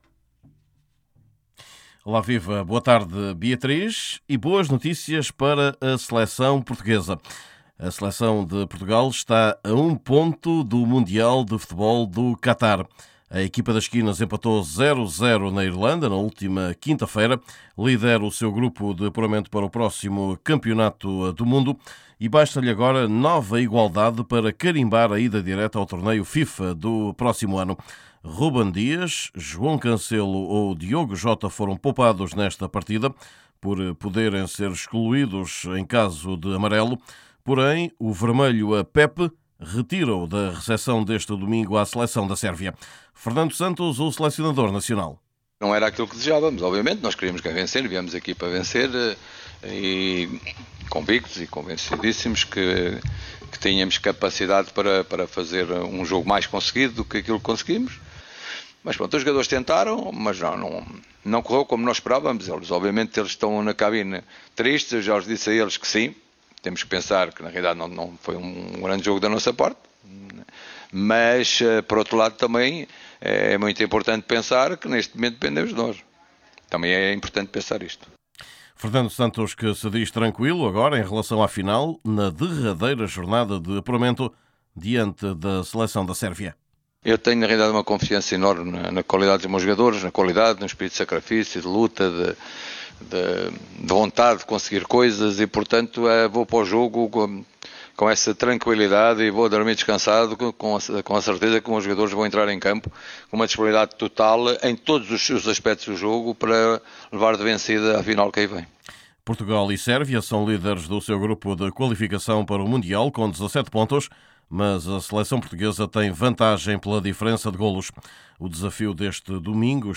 Neste boletim: Equipa das quinas empatou (0-0) na Irlanda e agora recebe, este domingo, a Sérvia. É o jogo decisivo entre os dois únicos candidatos à qualificação directa, no grupo A europeu, para o Campeonato do Mundo do Qatar.